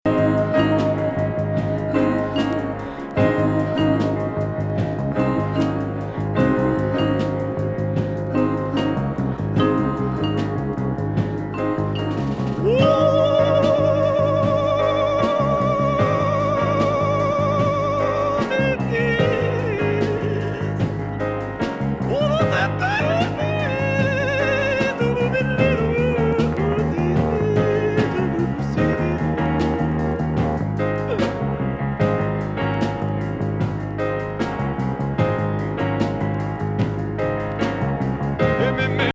ソウル～ファンクに傾倒したプログレッシブ・サウンド・プロダクション・ミーツ・錯乱コバイア・ストーリーでいよいよ独創的。